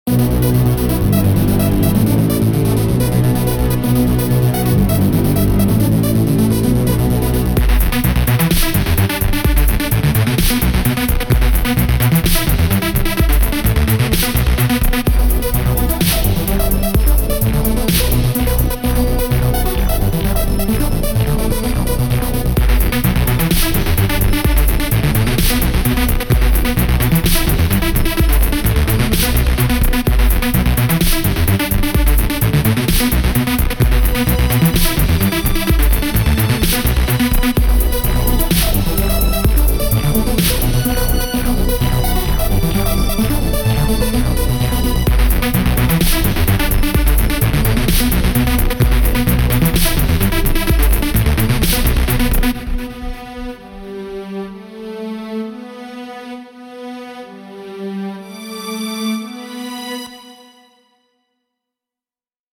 Dance Track